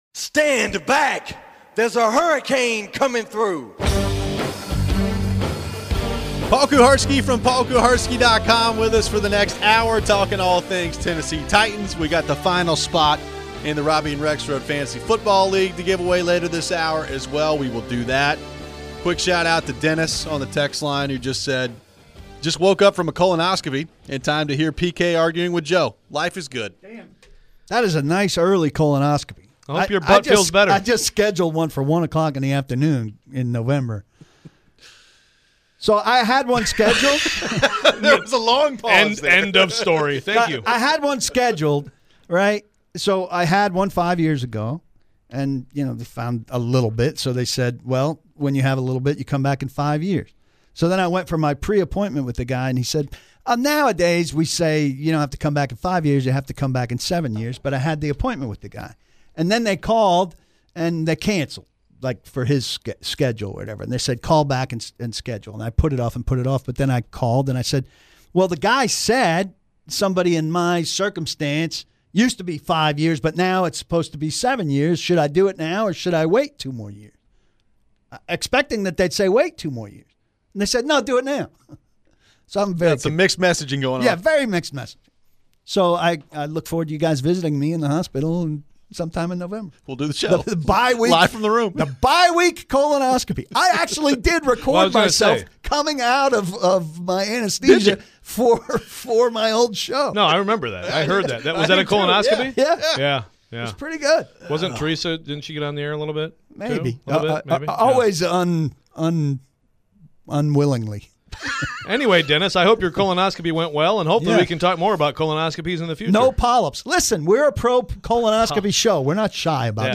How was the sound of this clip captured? We wrap up the week with your phones.